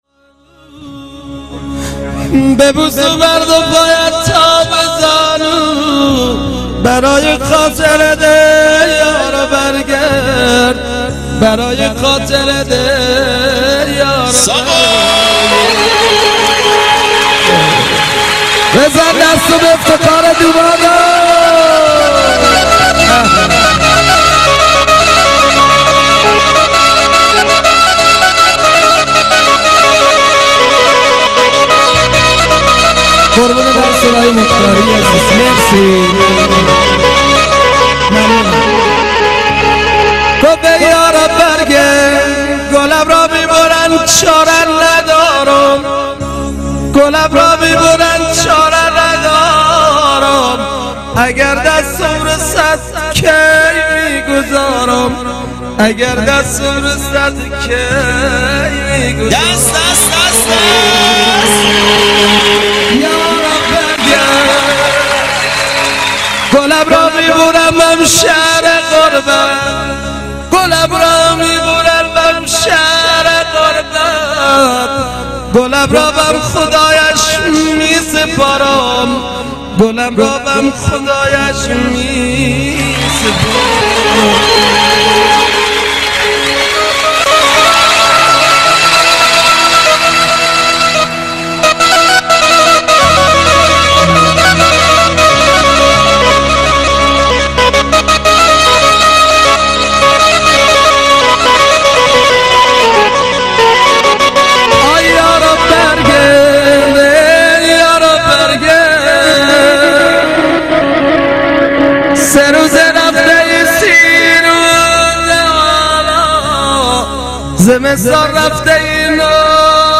یکی از خواننده های پرشور و پرانرژی کرمانجی